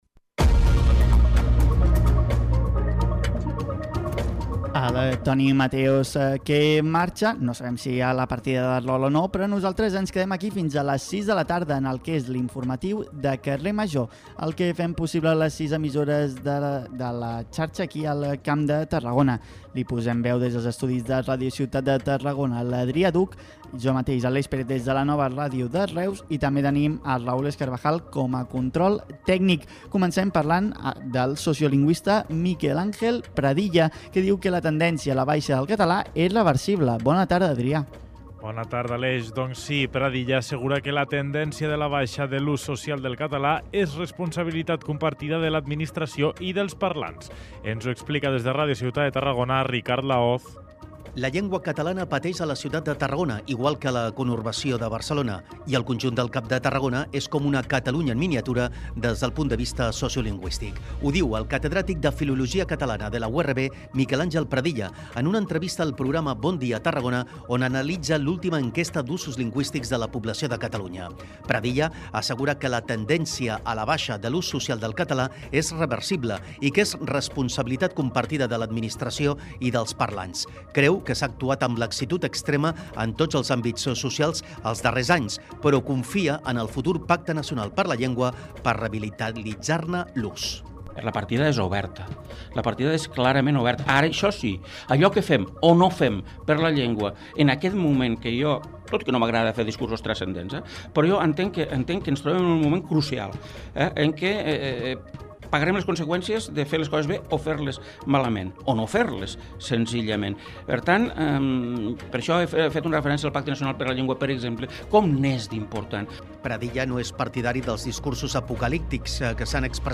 en una entrevista al programa ‘Bon dia Tarragona’ on analitza l’última Enquesta d’usos lingüístics de la població de Catalunya.